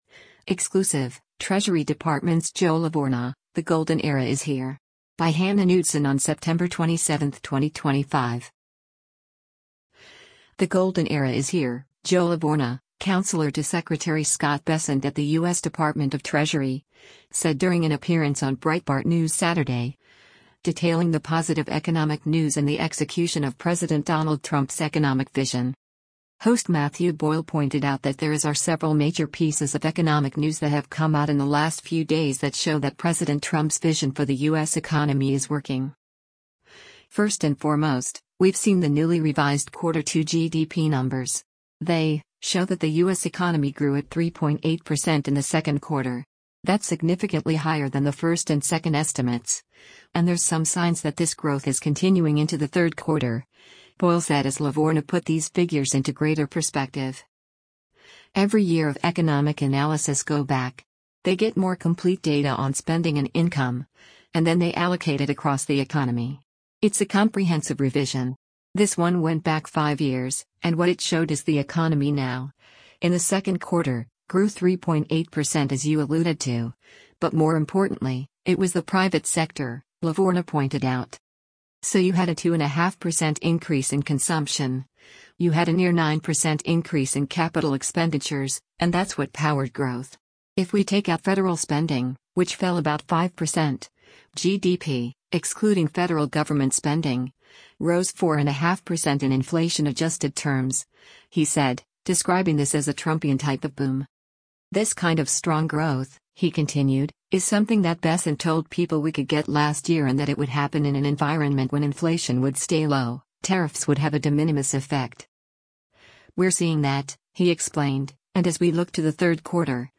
“The golden era is here,” Joe Lavorgna, counselor to Secretary Scott Bessent at the U.S. Department of Treasury, said during an appearance on Breitbart News Saturday, detailing the positive economic news and the execution of President Donald Trump’s economic vision.